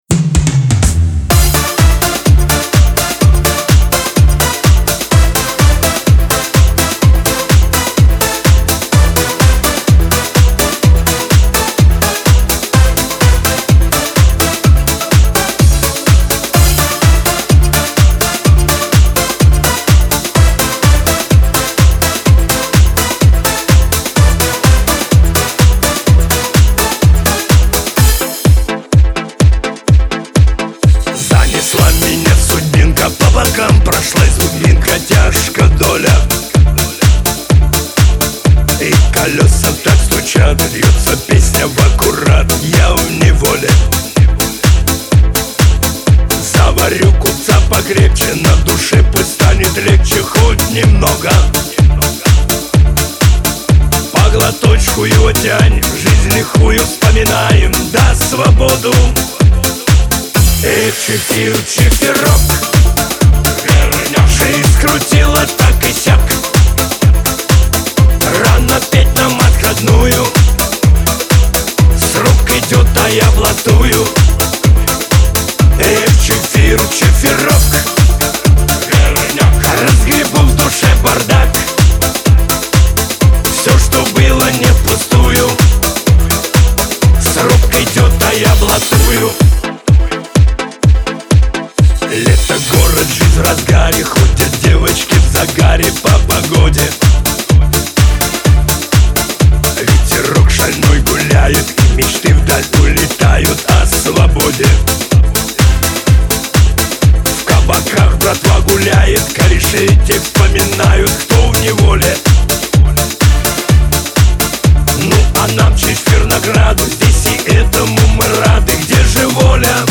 грусть , Шансон